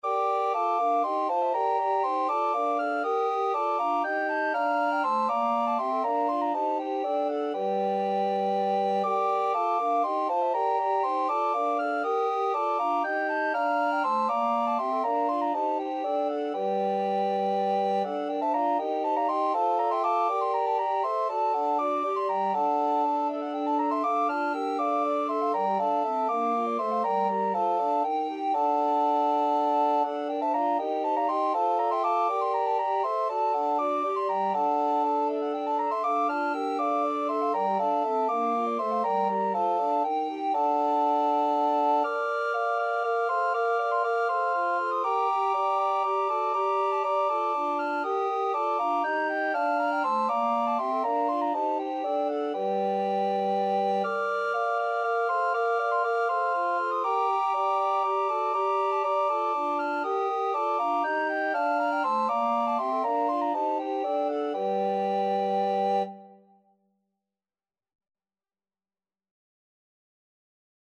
Free Sheet music for Recorder Ensemble
Soprano RecorderAlto RecorderTenor Recorder 1Tenor Recorder 2Bass Recorder
F major (Sounding Pitch) (View more F major Music for Recorder Ensemble )
3/2 (View more 3/2 Music)
Recorder Ensemble  (View more Intermediate Recorder Ensemble Music)
Classical (View more Classical Recorder Ensemble Music)